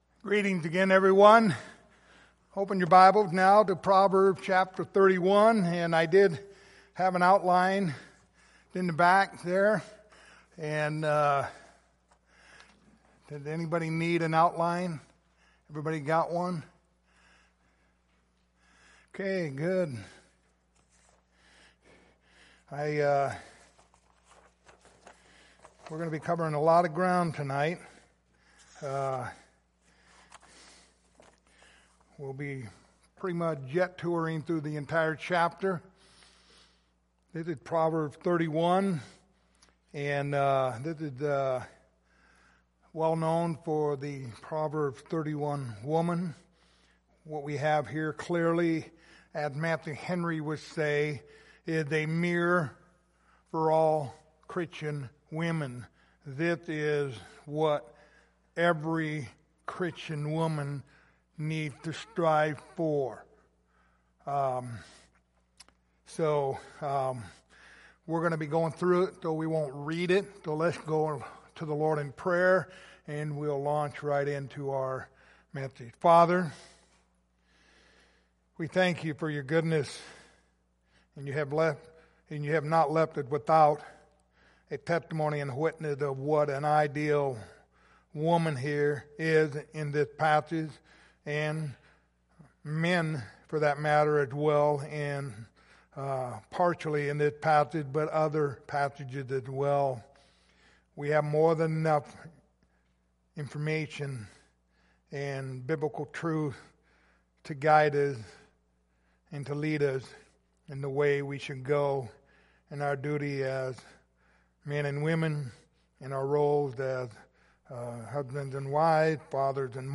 Passage: Proverbs 31:10-31 Service Type: Sunday Evening